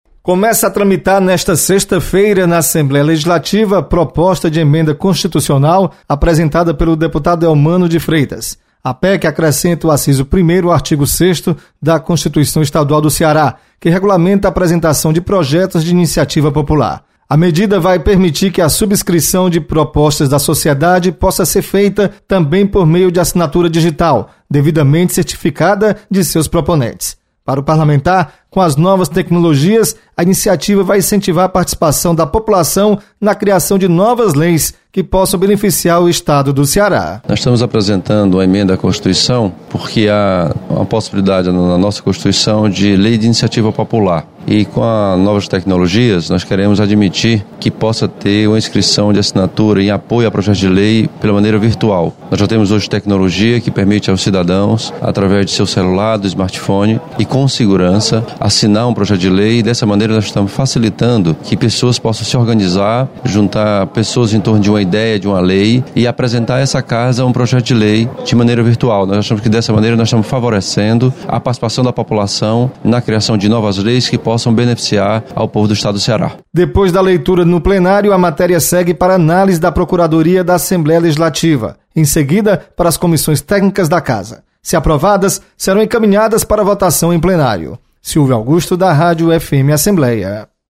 Projeto permite acesso da participação popular. Repórter